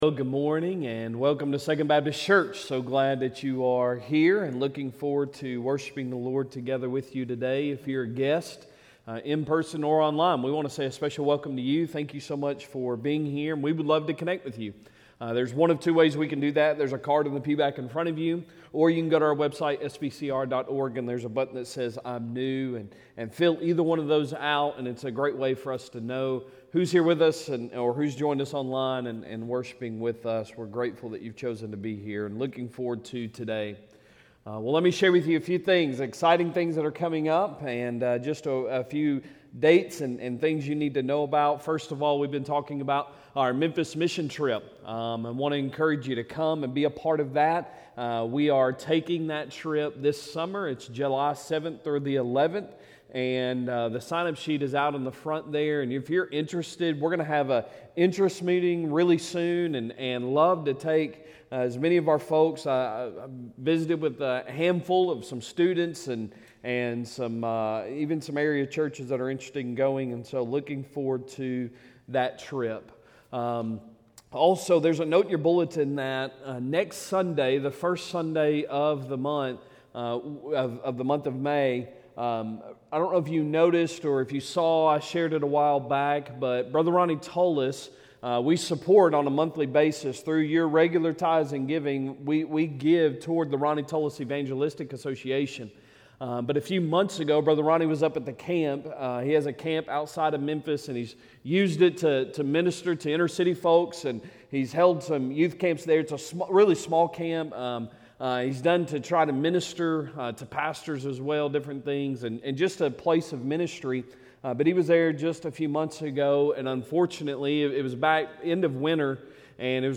Sunday Morning Sermon April 27, 2025